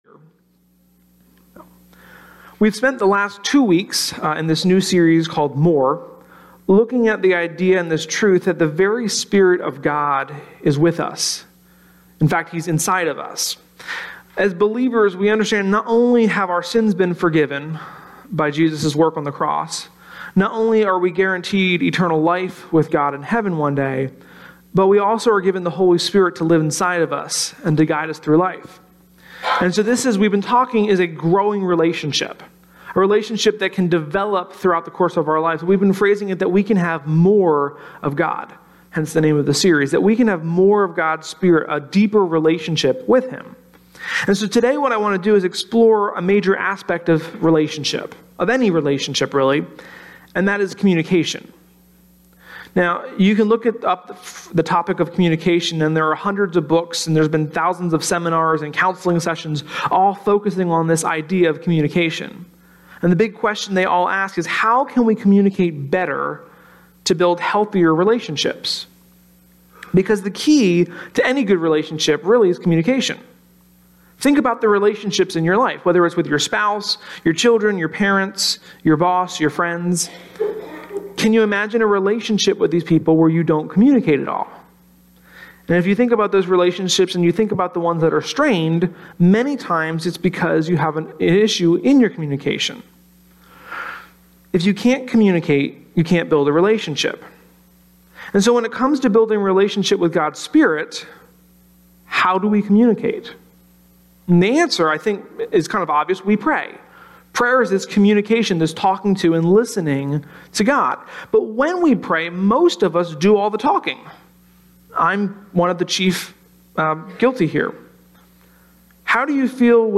sermon-1.21.18.mp3